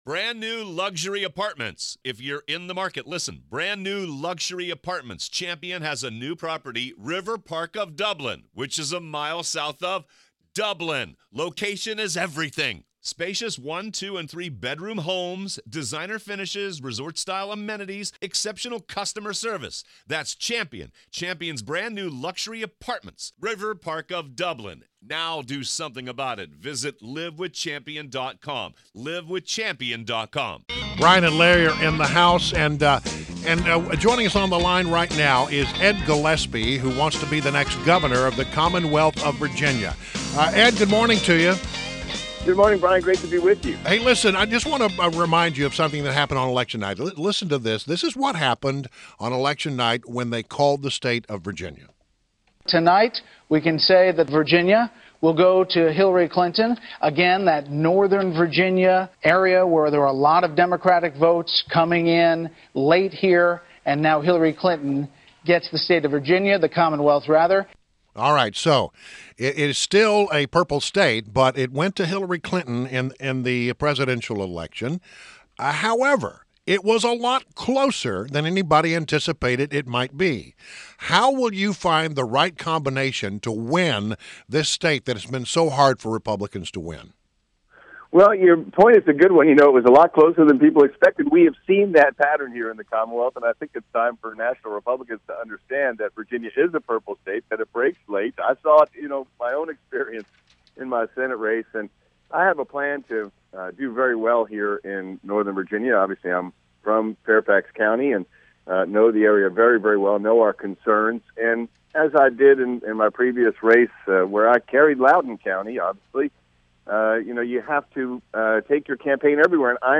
WMAL Interview - ED GILLESPIE - 11.23.16